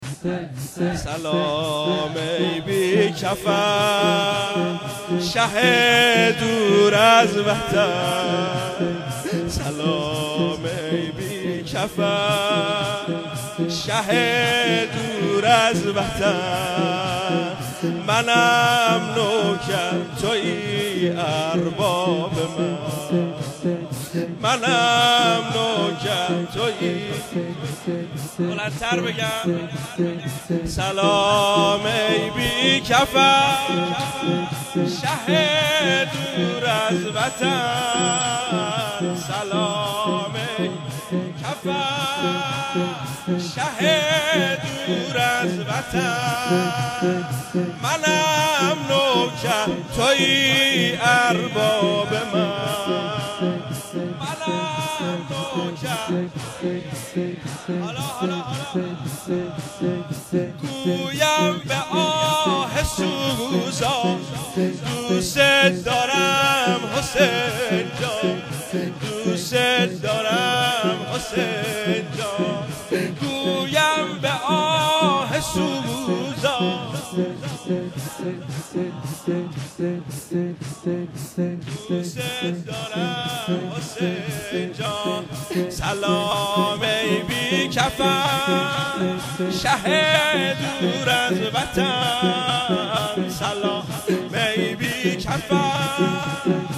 هیئت محبین انصار المهدی(عج)_محرم98
پیشواز محرم 1398